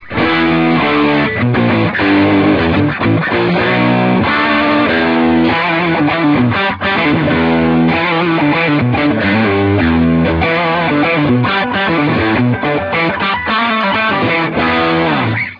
British Classic(Marshall JTM-45)
"Britclassic" is Brit Classic: gain 10; bass 7; mid 7; treble 7; no FX. Les Paul Custom w/bridge pickup.
britclassic.ra